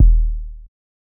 Kick Groovin 4.wav